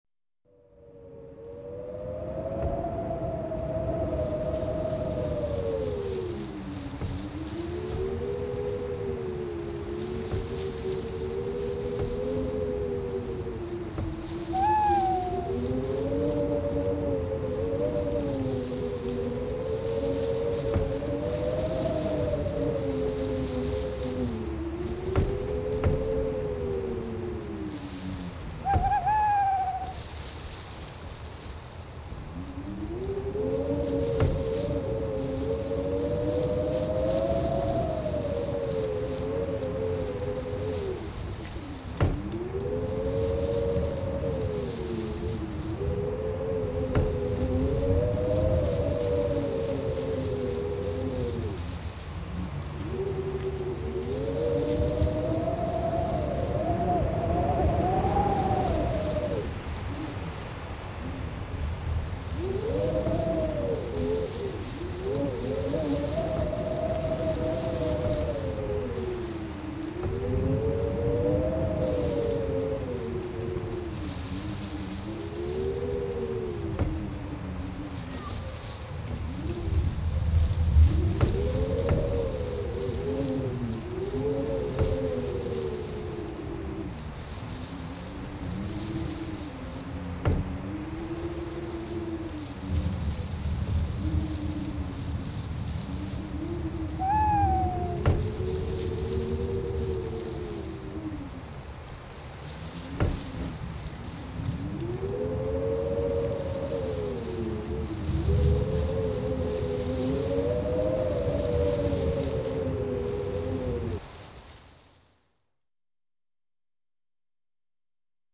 Scary Sounds - 64 - Wind And Rain Type